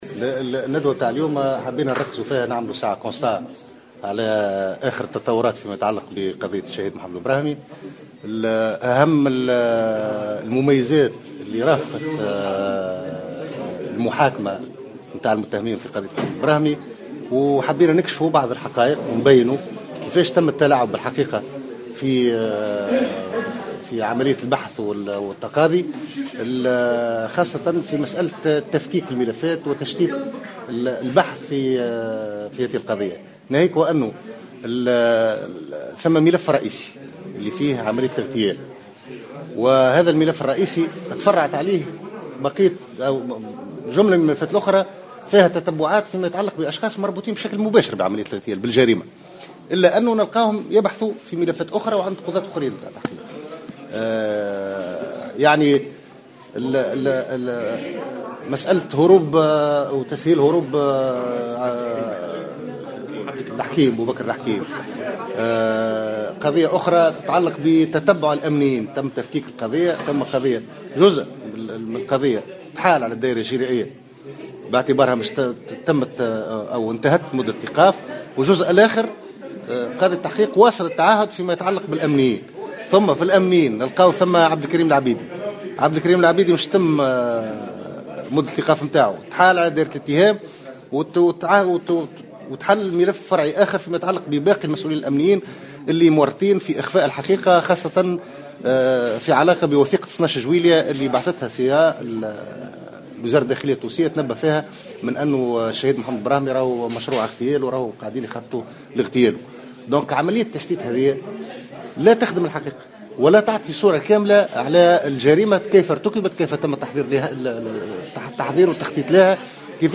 في تصريح للجوهرة أف أم، على هامش ندوة عقدتها اللجنة اليوم الاربعاء